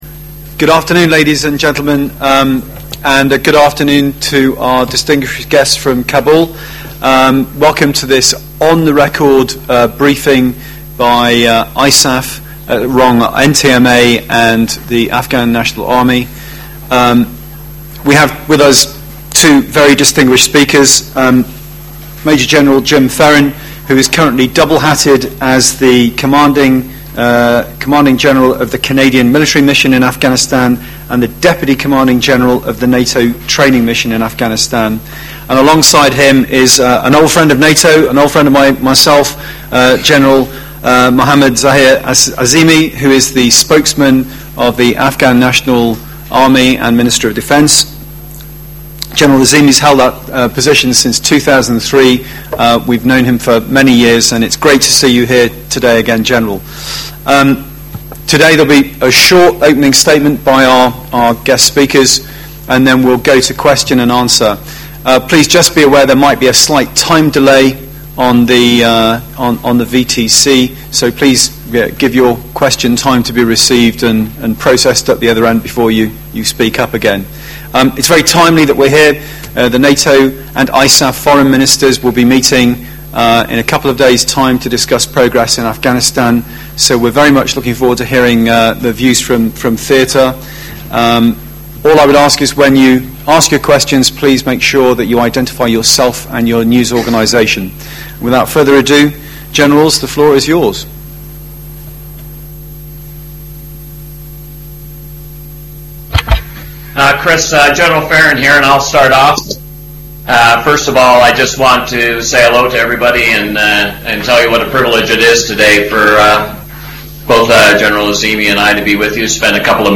Press briefing on the NATO Training Mission-Afghanistan (NTM-A)
On 3 December a press briefing was held at NATO Headquarters with the participation via video teleconference of Major General Jim Ferron, Commander, Canadian Mission and Deputy Commanding General, NATO Training Mission-Afghanistan (NTM-A) and Major General M. Zahir Azimi, Afghan lead for the NATO Training Mission – Afghanistan.